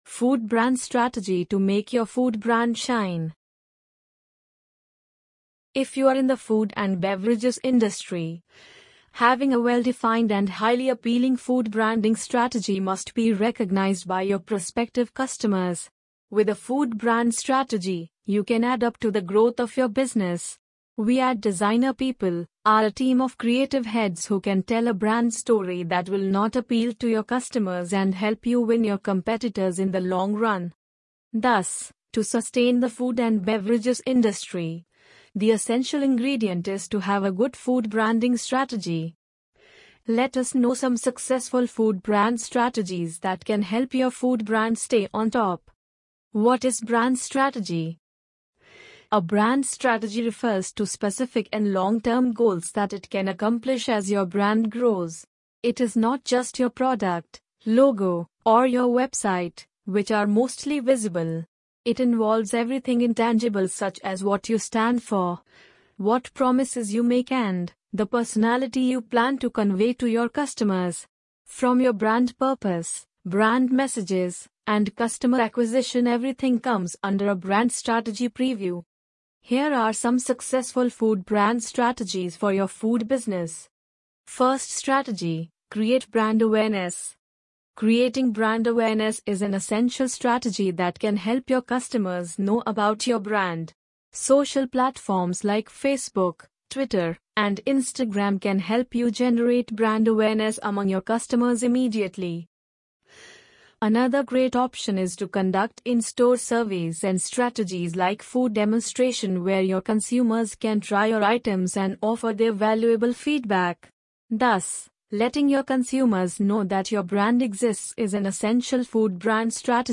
amazon_polly_5297.mp3